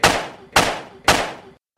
The villain in this clip uses a silencer that make his guns sound like hammer hitting sheet metal. While this may portray the most accurate sound of a silencer in this list, it is still far from the truth.
die-hard-2-down-the-rabbit-hole-gun-silencer-sound.mp3